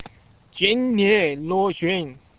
Start (High Speed Internet Only: mouse click the sentence number to hear its pronunciation in standard Chinese)